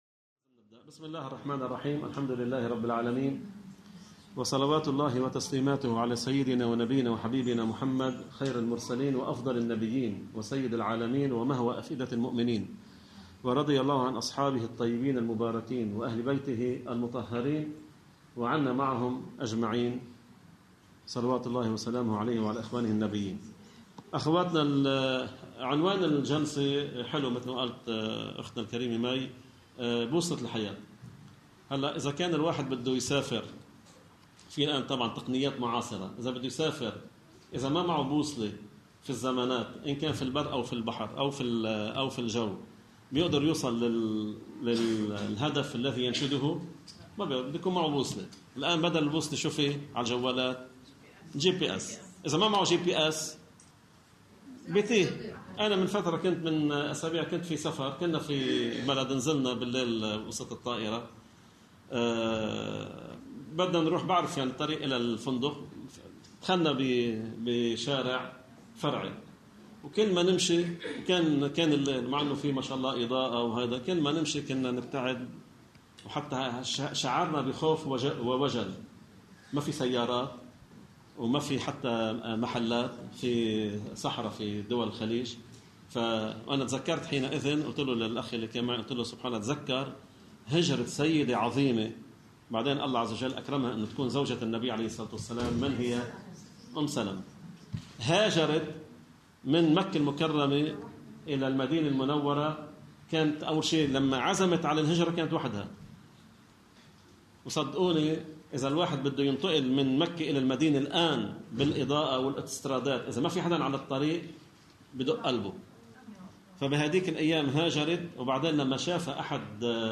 ضمن اللقاء الأول من سلسلة (بوصلة الحياة) مع الشابات التي ينظمها المنتدى الشبابي في صيدا.